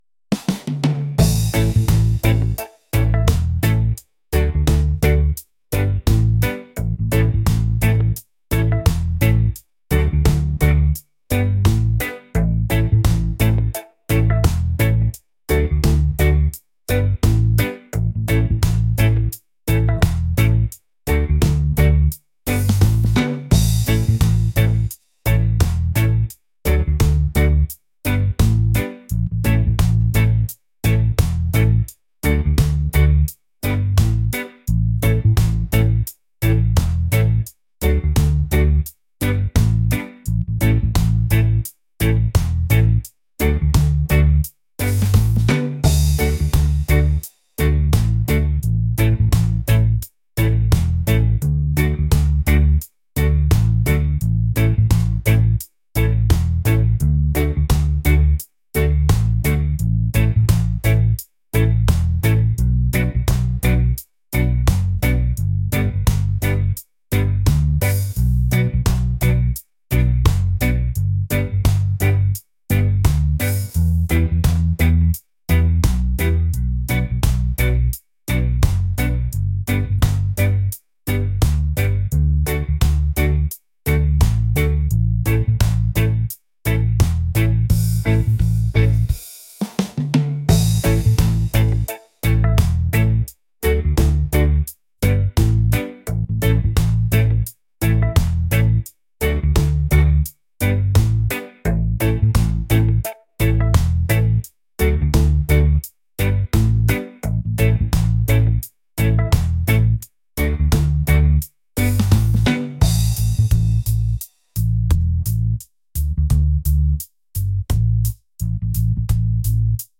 groovy | reggae | laid-back